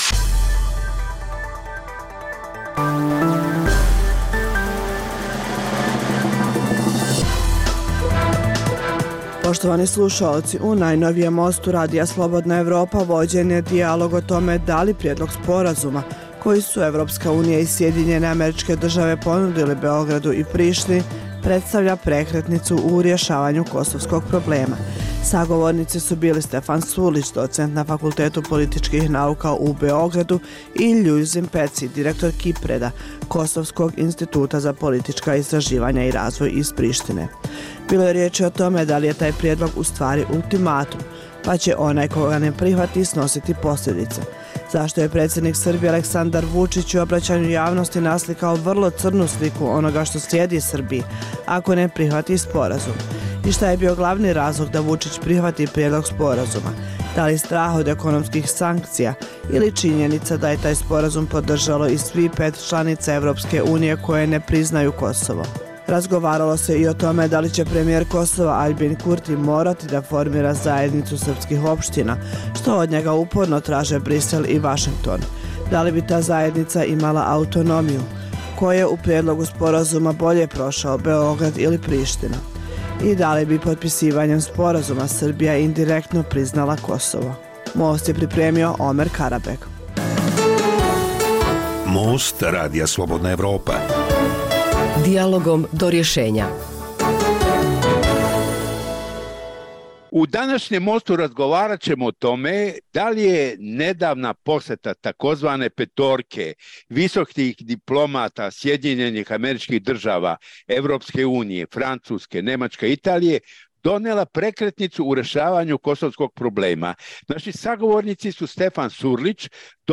U najnovijem Mostu Radija Slobodna Evropa vođen je dijalog o tome da li predlog sporazuma koji su Evropska unija i Sjedinjene Američke države ponudili Beogradu i Prištini, predstavlja prekretnicu u rešavanju kosovskog problema.